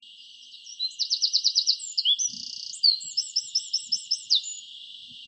We’ve put a recorder (home-made, experimental!) in the wood, and this is about what we’ve found.
Beautiful patterning in the spectrogram of a Wren song
The spectrogram therefore gives you important information – how much of a bird’s song you can hear and particularly how much you can’t. For me, much of the Wren’s song is inaudible, but fortunately the part that is is enough to be identifiable..
This is the actual sound that produced the spectrogram:
wren-filtered.mp3